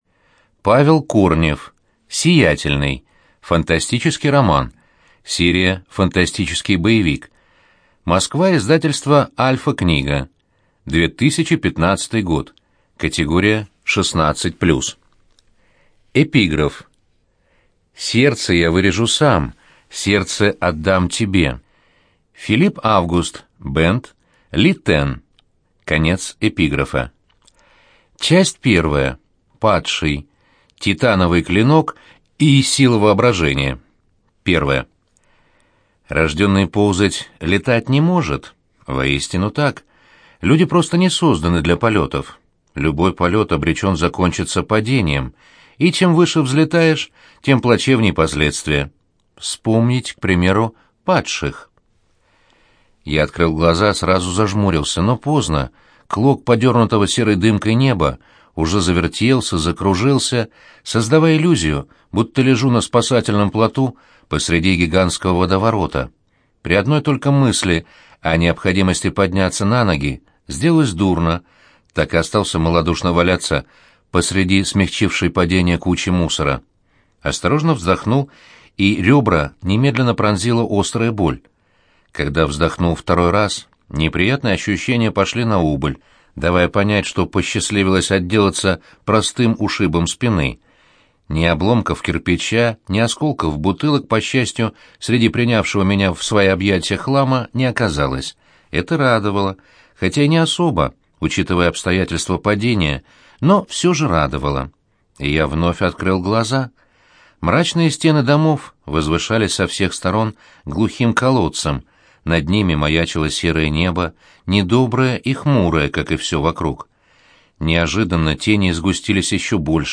ЖанрФантастика, Детективы и триллеры
Студия звукозаписиЛогосвос